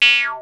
VARIOUS FILT 4.wav